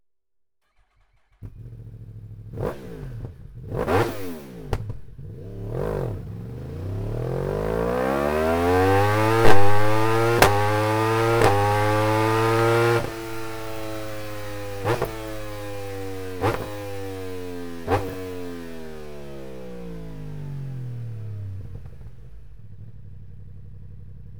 Akrapovic Slip-On Line (Titanium) Track Day Endschalldämpfer mit Titan-Hülle und Carbon-Endkappe, ohne Straßenzulassung; für
Der Schalldämpfer Track Day für die Yamaha YZF-R1 wurde insbesondere für den Einsatz auf Rennstrecken, auf denen geringere Lärmwerte erforderlich sind, entwickelt.
Sound Akrapovic Slip-On